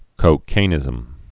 (kō-kānĭzəm)